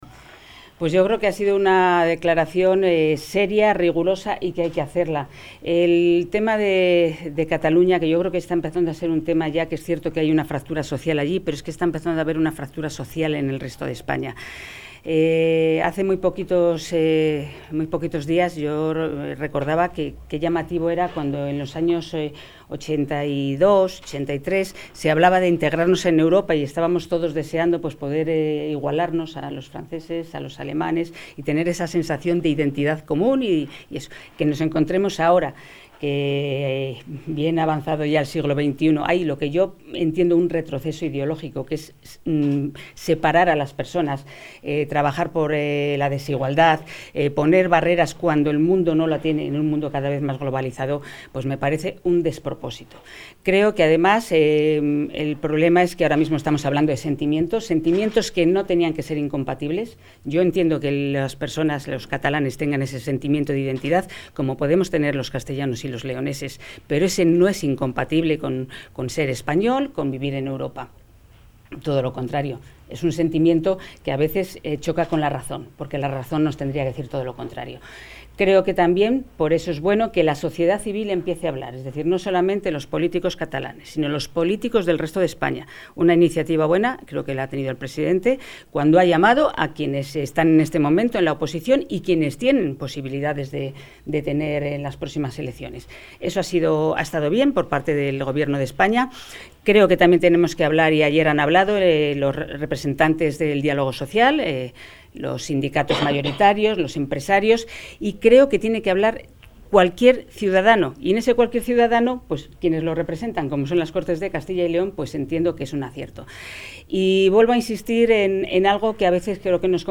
Valoración de la vicepresidenta y portavoz de la Junta de Castilla y León, Rosa Valdeón, sobre la declaración 'Por el futuro de todos'